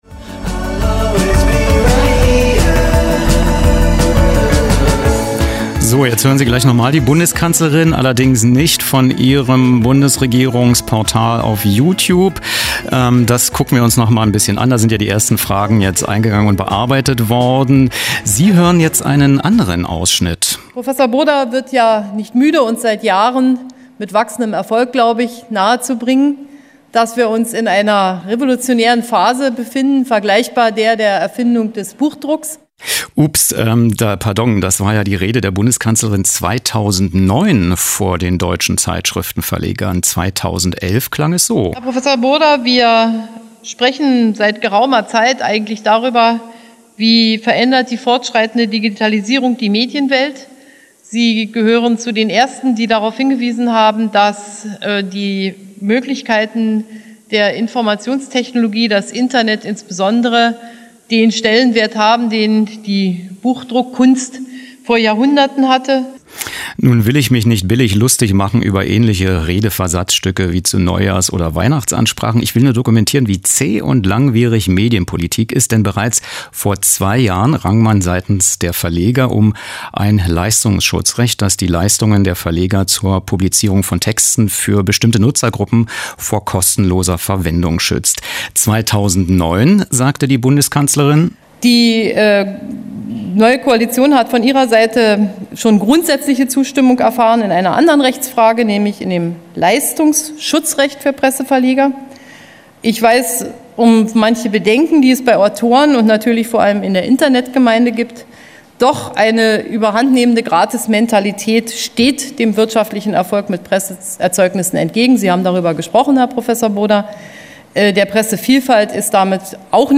O-Ton:
* Redeausschnitte 2009/2011 Dr. Angela Merkel, Bundeskanzlerin
Interviews mit: